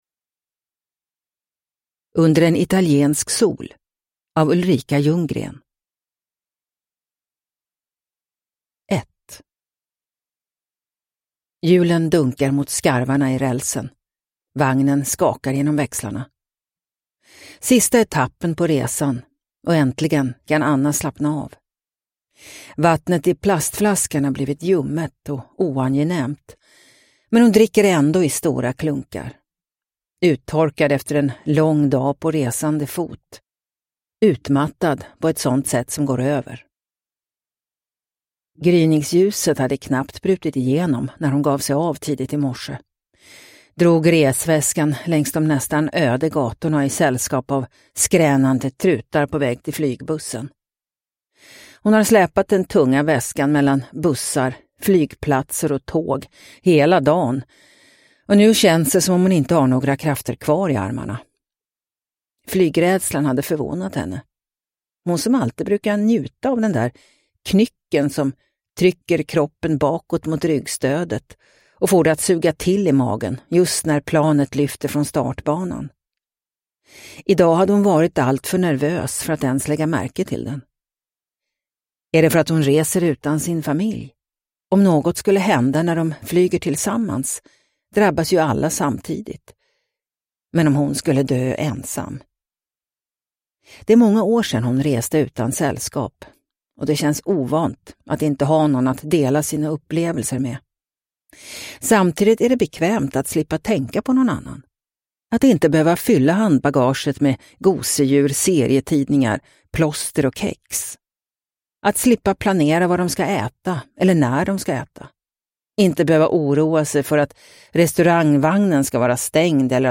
Under en italiensk sol – Ljudbok – Laddas ner